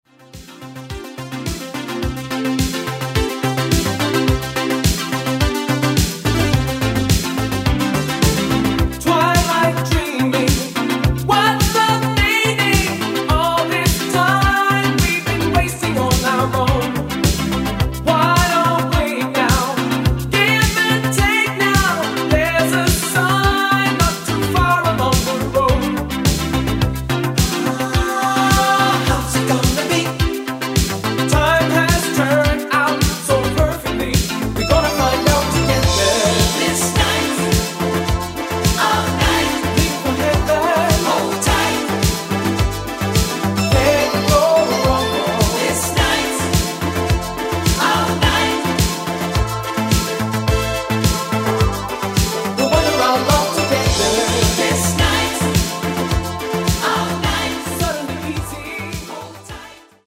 Recorded at Umbi studio & Morning studio, Italy